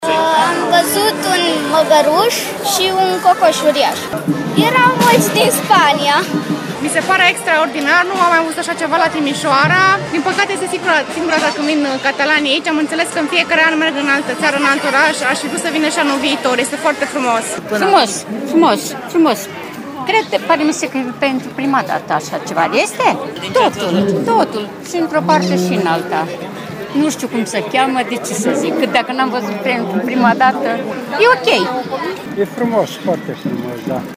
vox-uri-festival-catalani.mp3